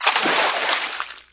tubsplash1.wav